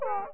eraser1.mp3